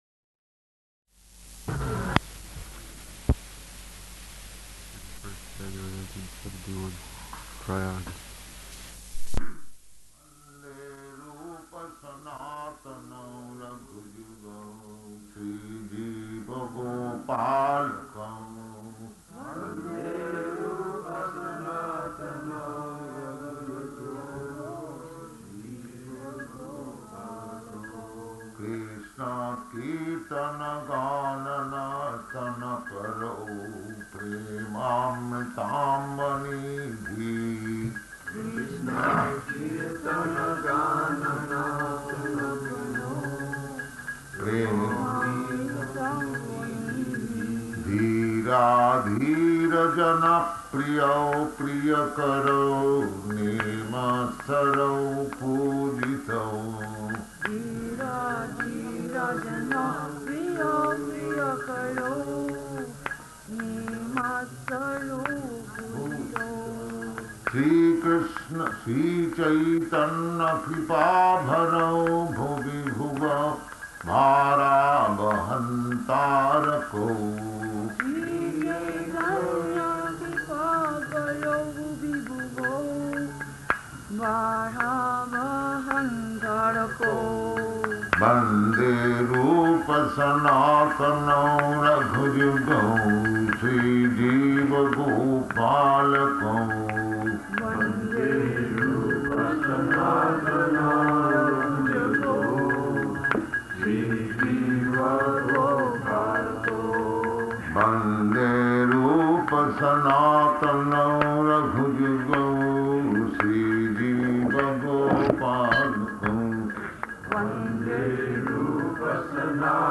Śrīmad-Bhāgavatam 6.2.48 Māgha-melā --:-- --:-- Type: Srimad-Bhagavatam Dated: January 31st 1971 Location: Prayaga Audio file: 710131SB-PRAYAGA.mp3 Devotee: [introducing recording] Thirty-first January, 1971, Prayāga.
[sings Śrī Śrī Ṣaḍ-gosvāmy-aṣṭaka with devotees responding]